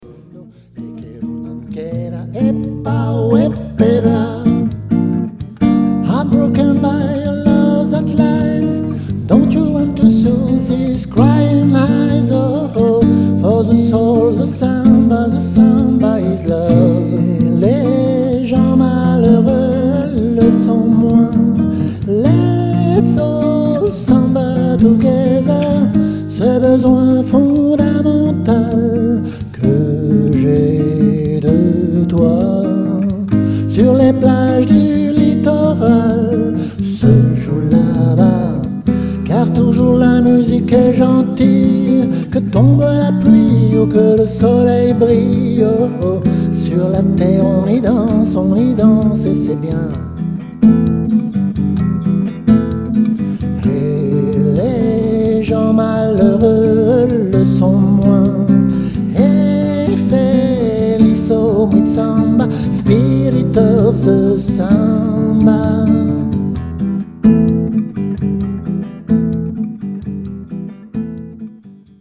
version acoustique